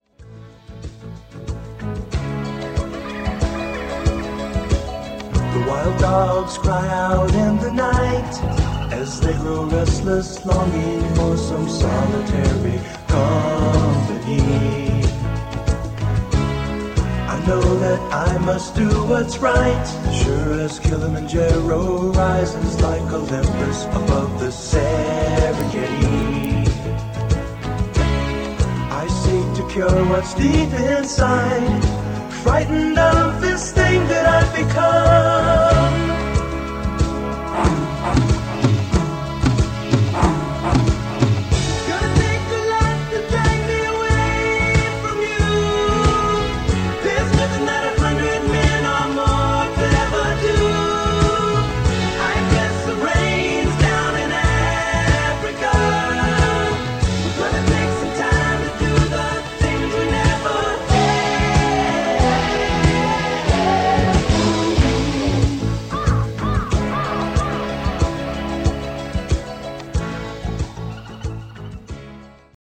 Below is a test recording made with D-E65 and played back by it:
Hitachi-D-E65-Test-Recording.mp3